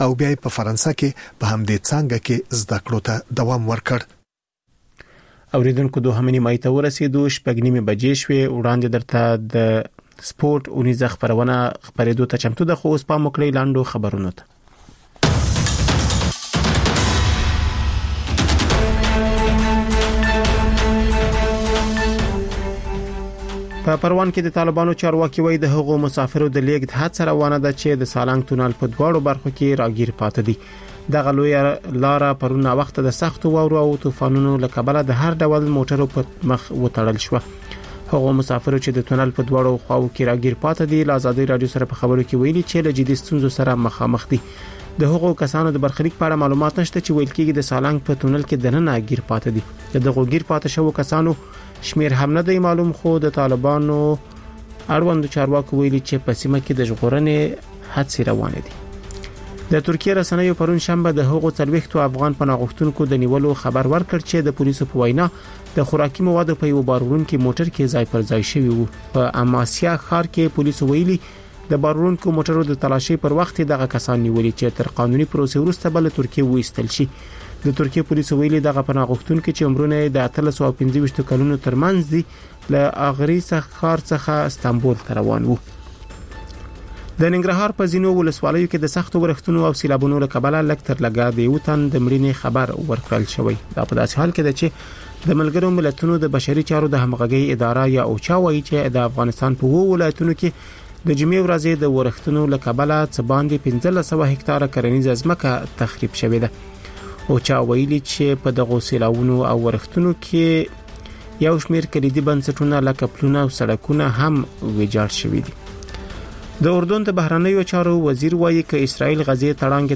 لنډ خبرونه - د لوبو له میدانه (تکرار)